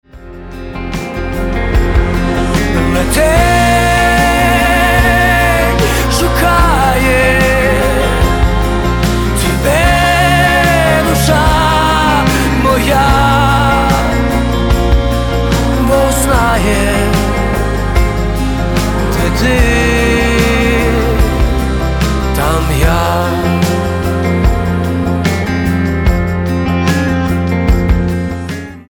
• Качество: 320, Stereo
гитара
романтичные
лирические
украинский рок